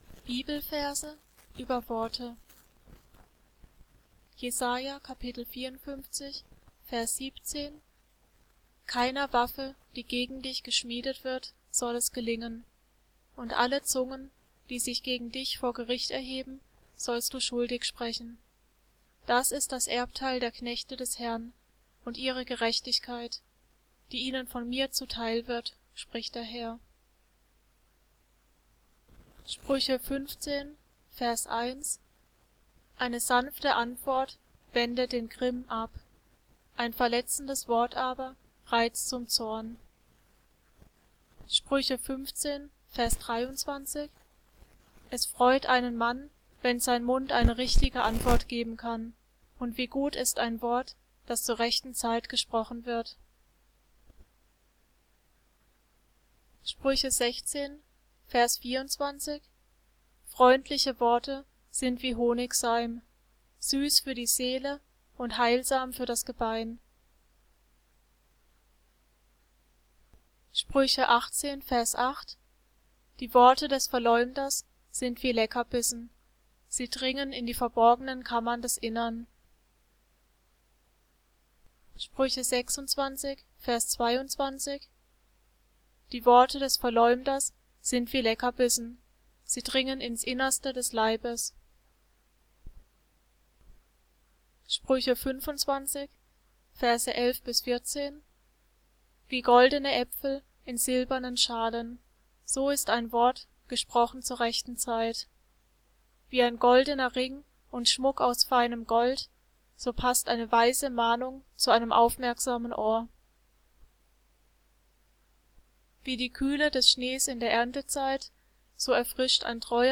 Gesprochene und gesungene Bibelverse